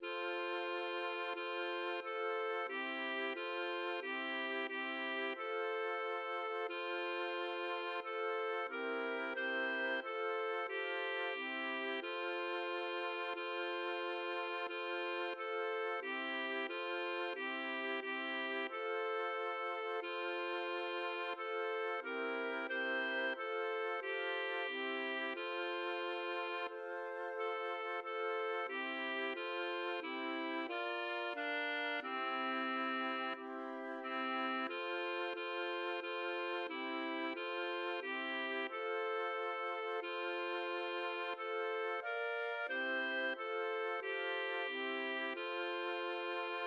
Altgaretisches Lied aus Fremmelsfelde